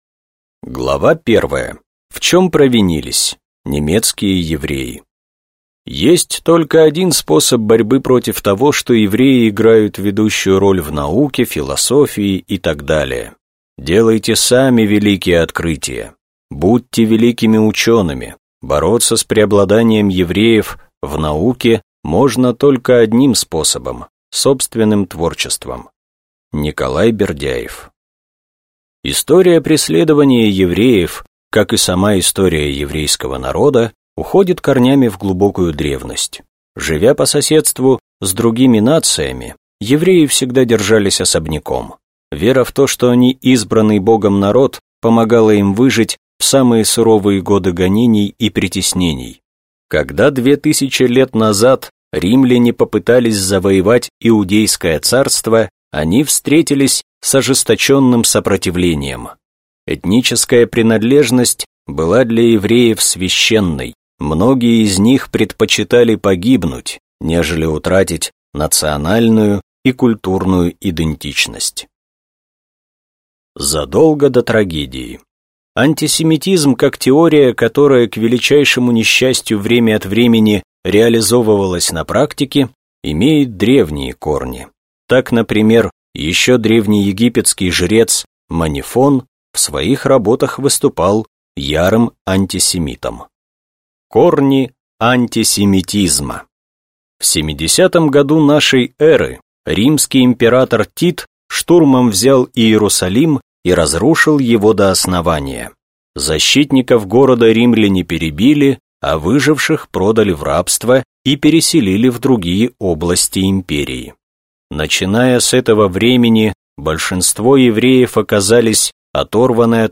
Аудиокнига Холокост. Уроки истории | Библиотека аудиокниг